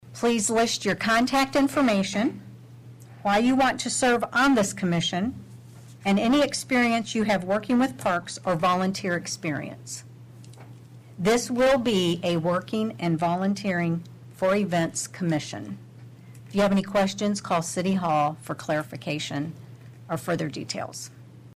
Regarding the Parks and Recreation Advisory Commission, Atlantic Mayor Grace Garrett asks those interested in serving on the commission to please bring a letter of interest in a sealed envelope to City Hall.